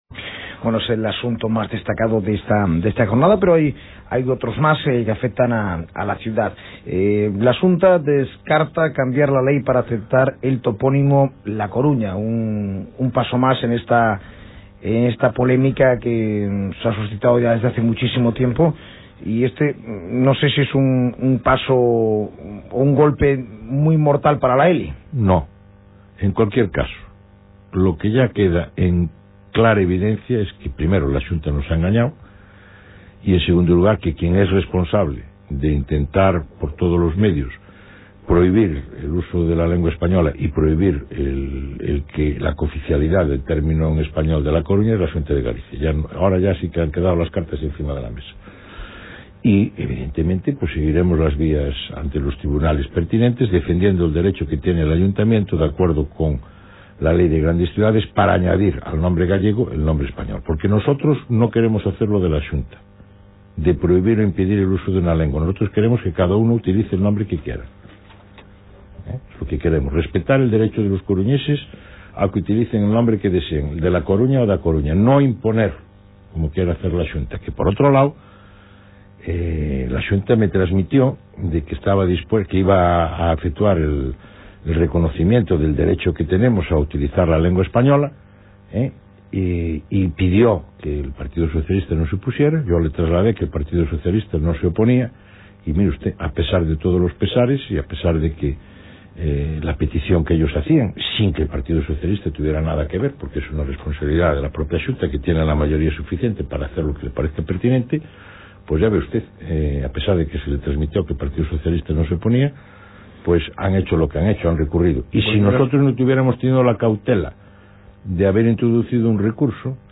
Título: Declarações de Francisco Vázquez Vázquez, presidente da Câmara Municipal da Corunha, a respeito do topónimo da cidade, e da Lei de Normalización Lingüística. Fragmento de entrevista.
Características da gravação: Gravada em formato digital  MP3 por sintonizador portátil FM a 128 Kbps, 44 Khz, estéreo. Convertida com dbPowerAmp (gratuíto) a 24 Kbps, 11 Khz, mono.